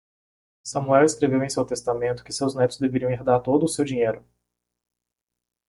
Pronounced as (IPA) /eʁˈda(ʁ)/